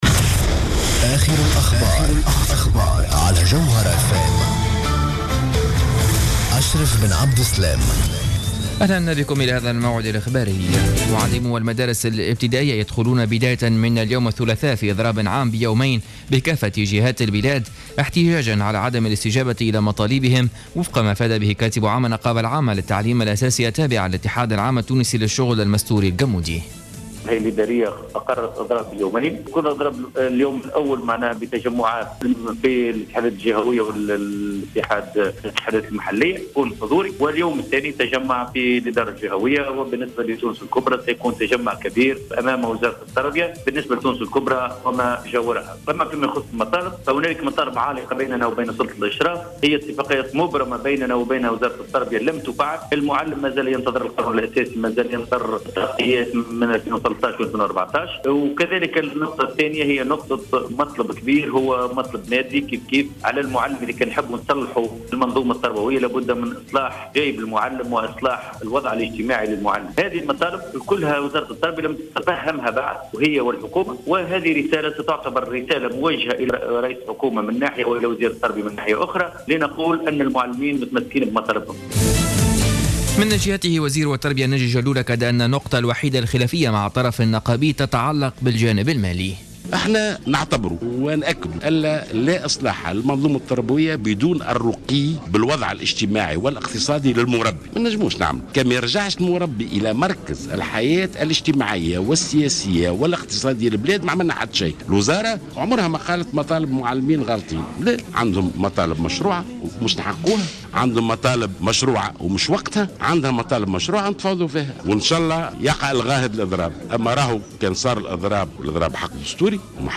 نشرة أخبار منتصف الليل ليوم الثلاثاء 12 ماي 2015